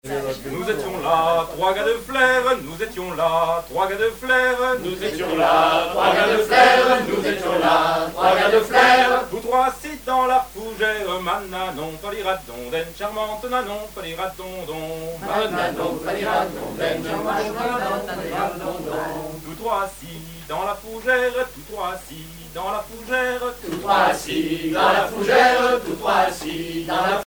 Usage d'après l'analyste gestuel : danse
Genre laisse
Chansons et commentaires
Pièce musicale inédite